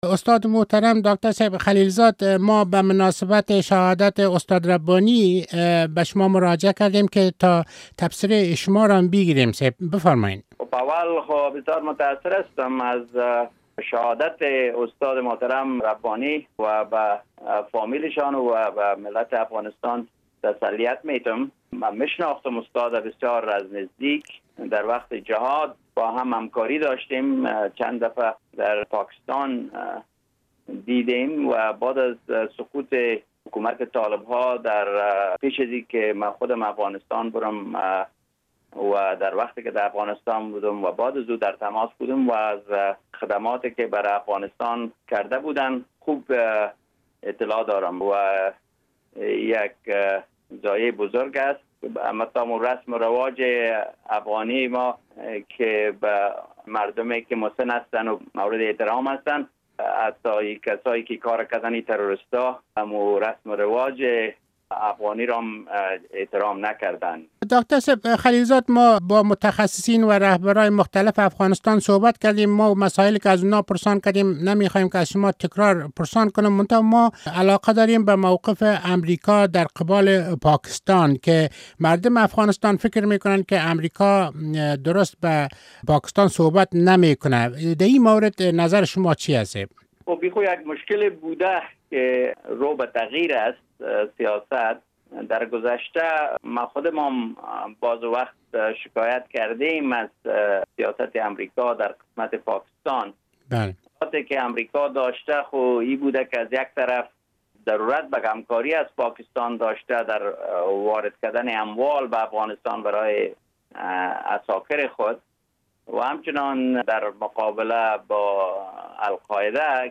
مصاحبه با زلمی خلیل زاد در مورد روابط امریکا و پاکستان(مصاحبه کامل)